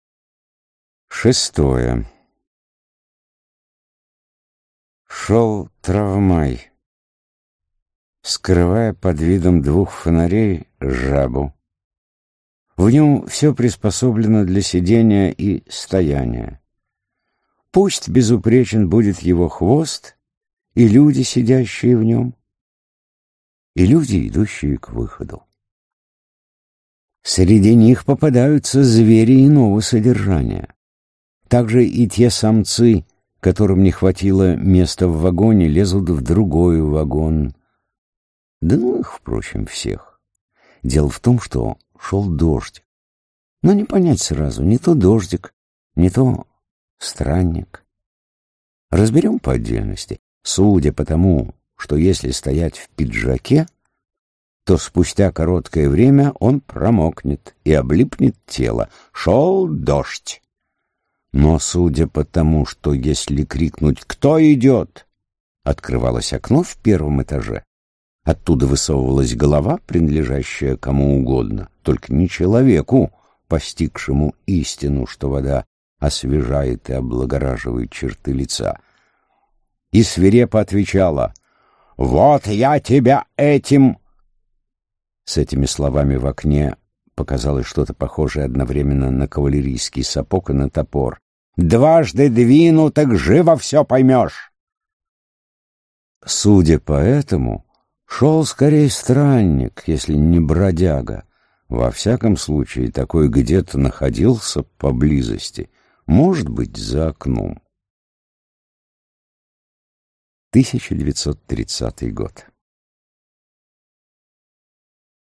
Аудиокнига Даниил Хармс. Анекдоты и рассказы | Библиотека аудиокниг